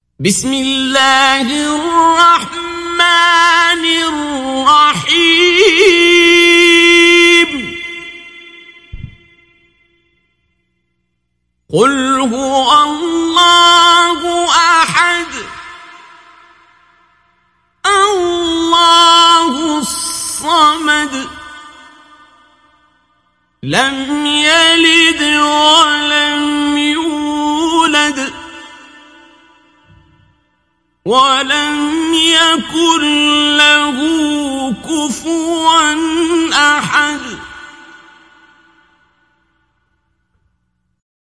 Download Surat Al Ikhlas Abdul Basit Abd Alsamad Mujawwad